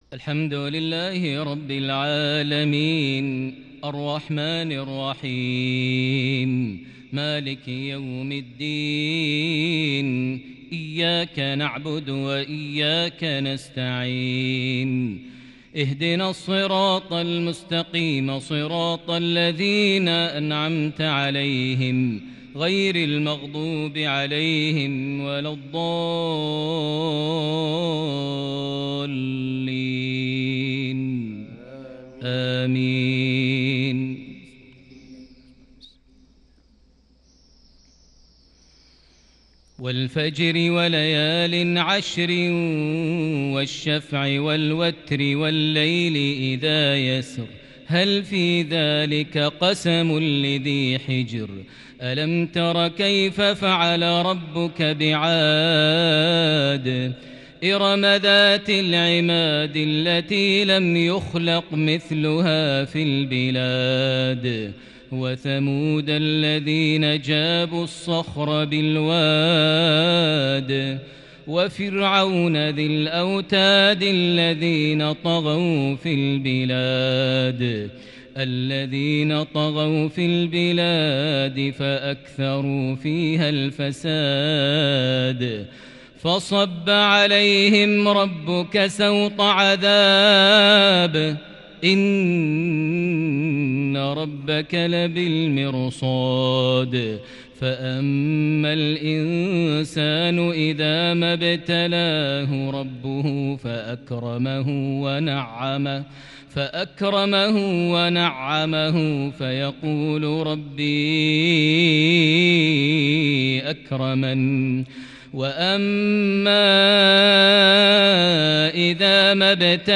تلاوة فريدة للغاية بالكرد البديع لسورة الفجر | مغرب 22 ربيع الأول 1442هـ > 1442 هـ > الفروض - تلاوات ماهر المعيقلي